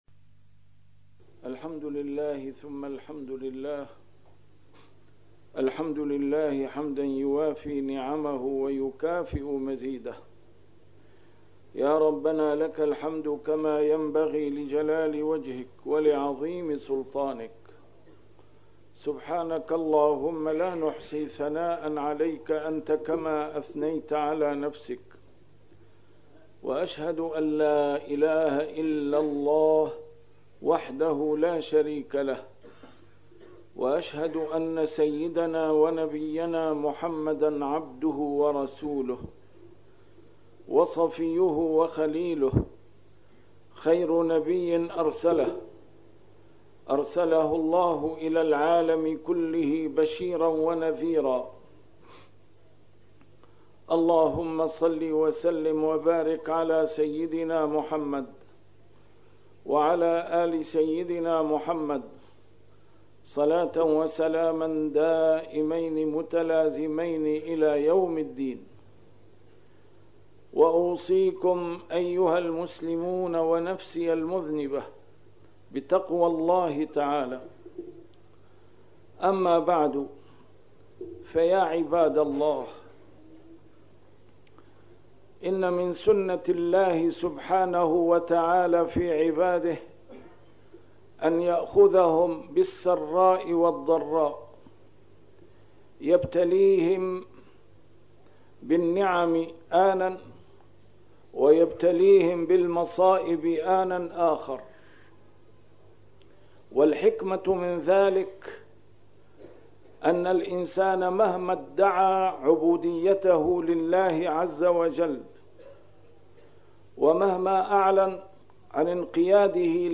A MARTYR SCHOLAR: IMAM MUHAMMAD SAEED RAMADAN AL-BOUTI - الخطب - تحذير متجدد .. من خطر محدق